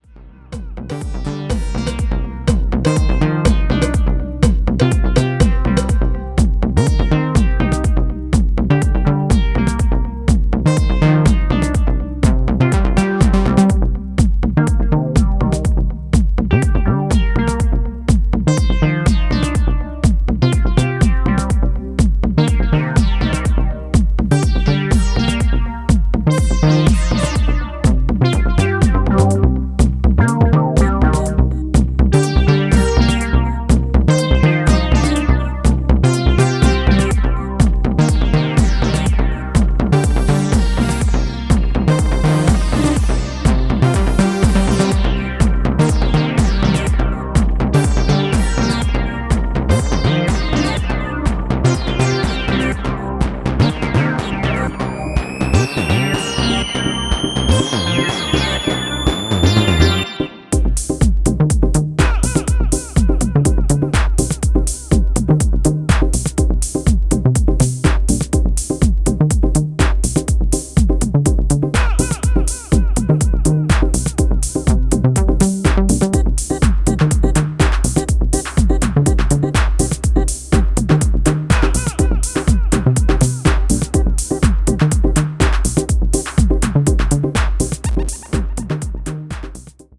全体的に低重心のグルーヴ感もナイスで、Nu Disco方面の音好きもぜひチェックしてみてください。